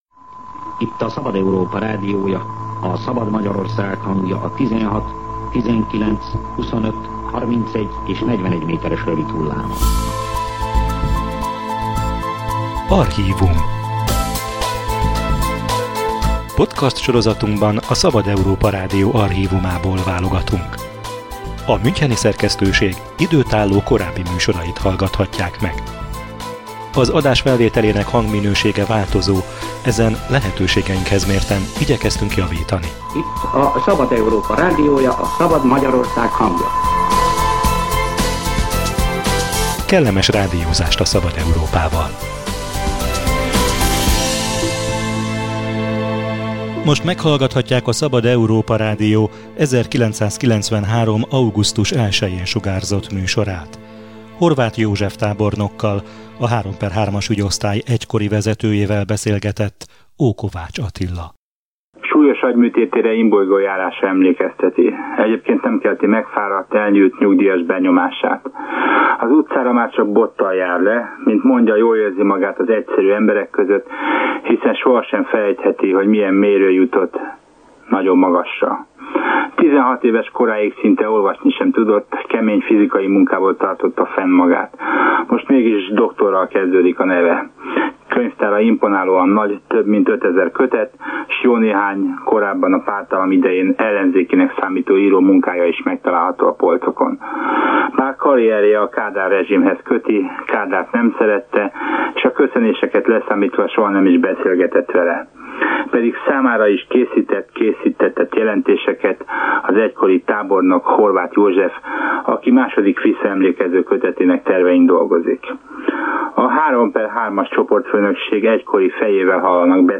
Kihallgatás, tanúverés, kikényszerített vallomások: ez ugrik be, ha a pártállami III/III-as ügyosztály nevét hallja az ember. Ebből semmi sem igaz – állította a Szabad Európa Rádió 1993. augusztus 1-jei műsorában Horváth József tábornok, az egység egykori vezetője, aki a működésükről beszélt.